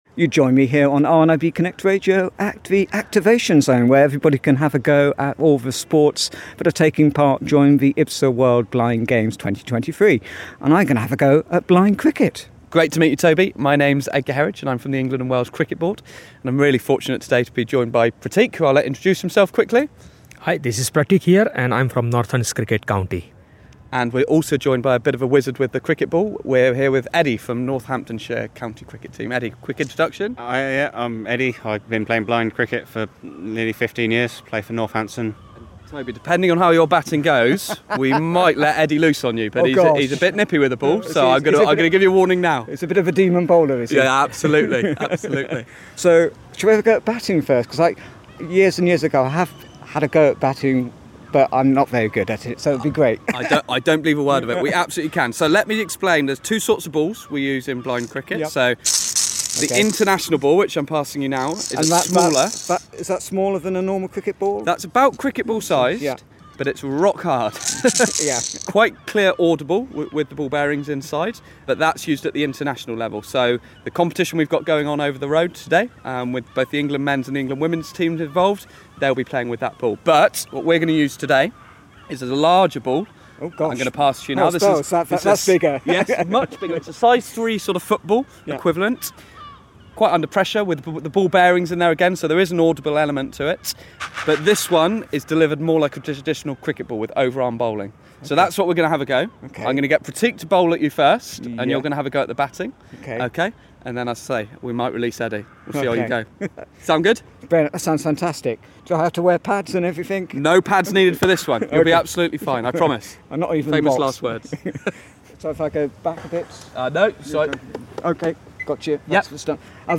IBSA World Blind Games 2023, Activation Zone - Blind Cricket Lesson